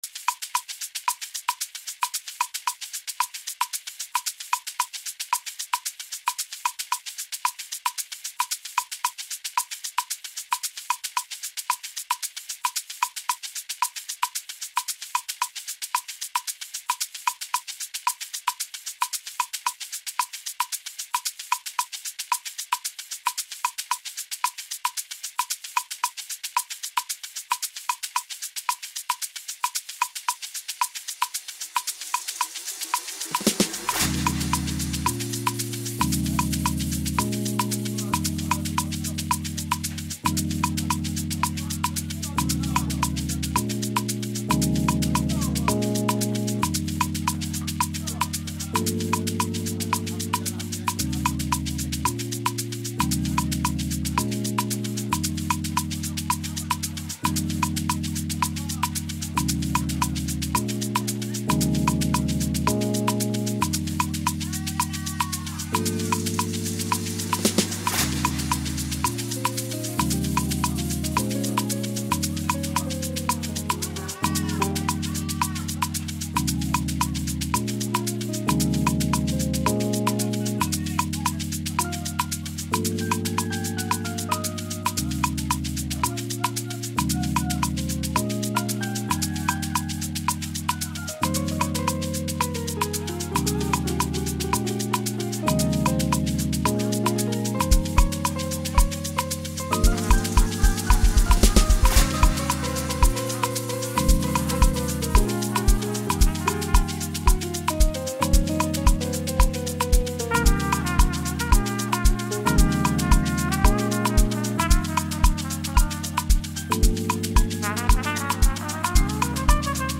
captivating and harmonious tune
producing a smooth and impressive final sound throughout.